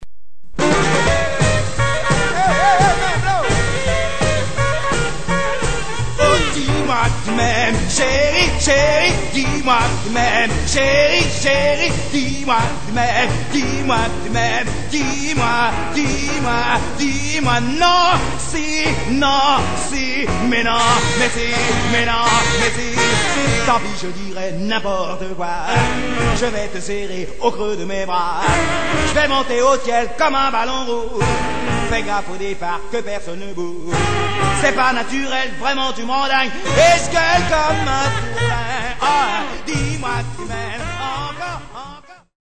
Rock and Roll à la française...